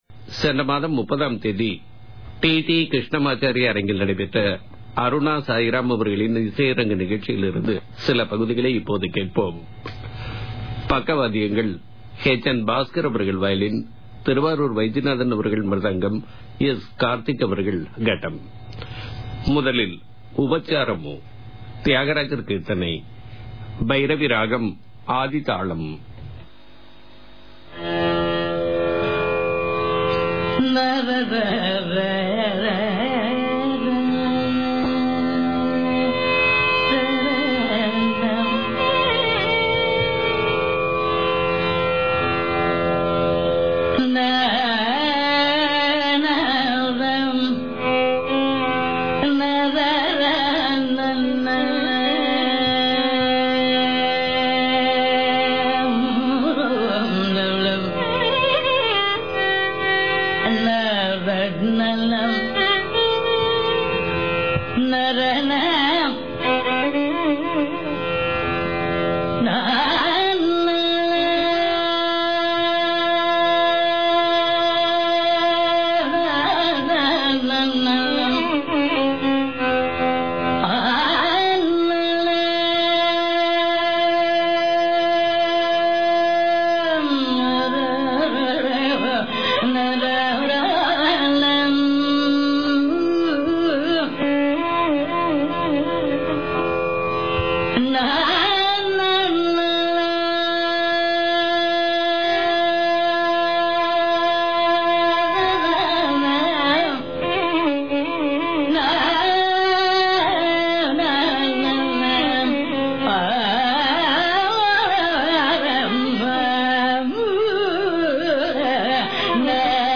The whole concert
Violin
Mridangam
Ghatam